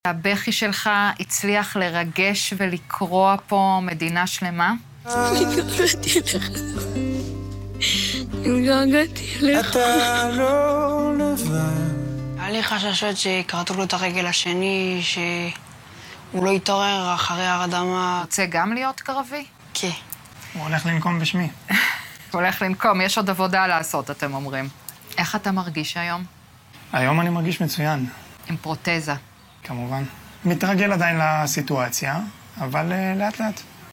״היו לי חששות שכרתו לו את הרגל השנייה״: ריאיון עם האחים שריגשו מדינה שלמה.